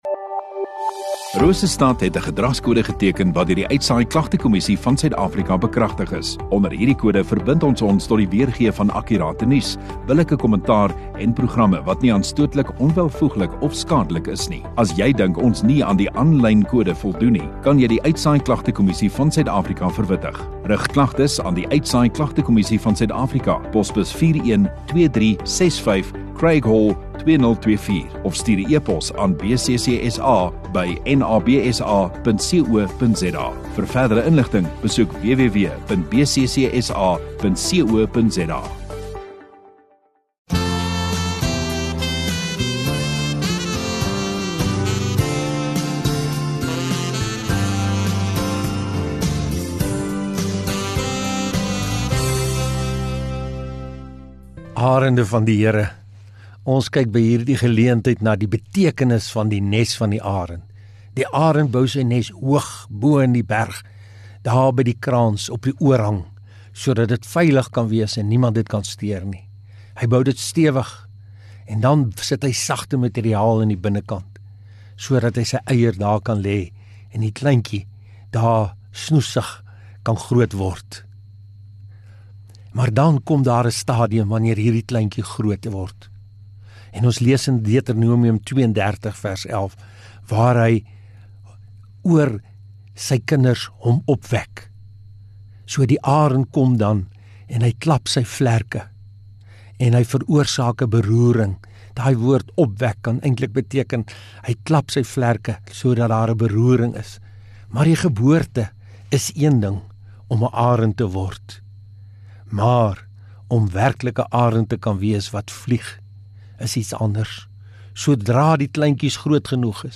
Radio Rosestad View Promo Continue Radio Rosestad Installeer Oordenkings 2 Jul Woensdagoggend